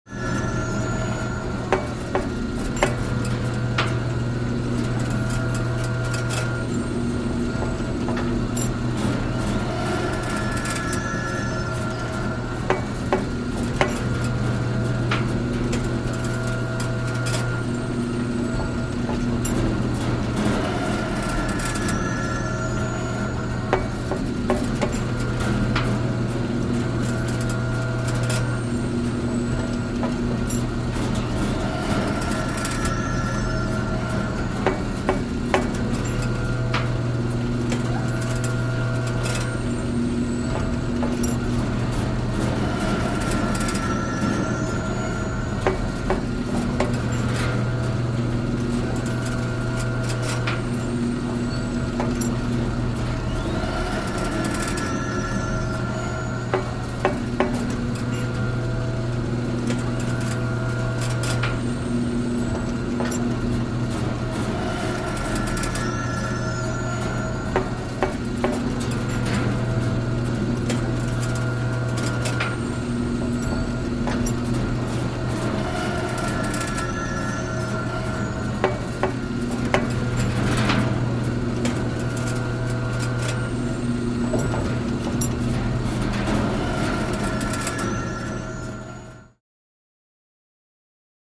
OilDerrickRunning BFX021401
Oil Derrick; Running Steady, Close Perspective